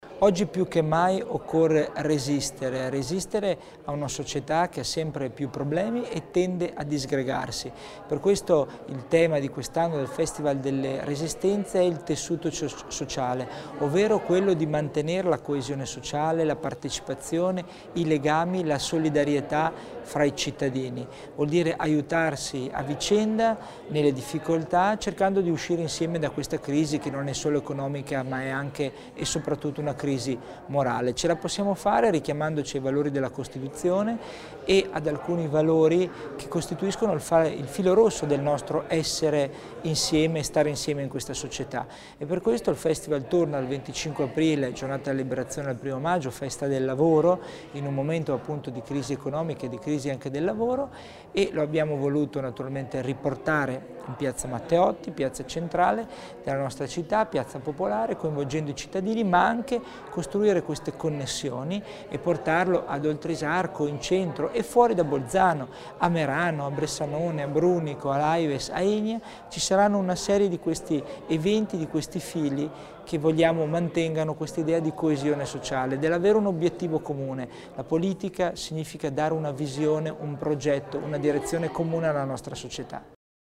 L'Assessore alla Cultura Italiana Christian Tommasini spiega l'importanza del Festival delle Resistenze 2013